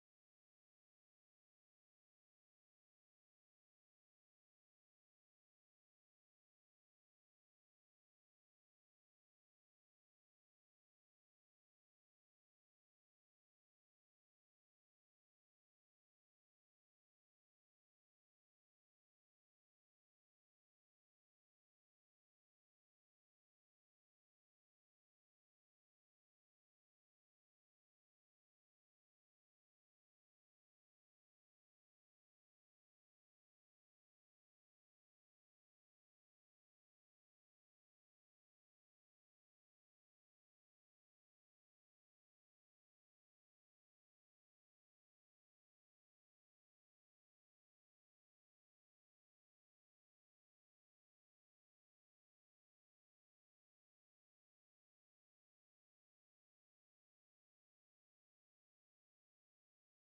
digital_silence.mp3